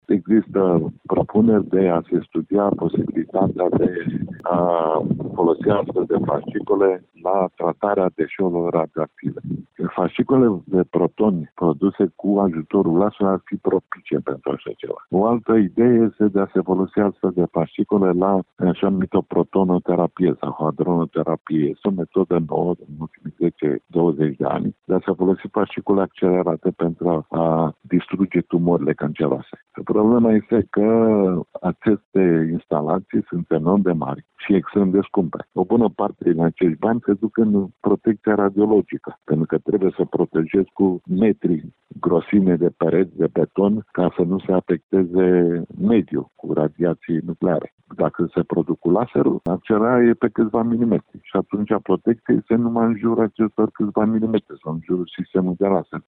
Fasciculele de protoni emise la puteri mari ar putea ajuta la rezolvarea problemei deșeurilor radioactive și chiar la dezvoltarea unor noi terapii de tratare a cancerului, spune acad. Victor Zamfir, inițiatorul coordonator al proiectului „Laserul de la Măgurele”: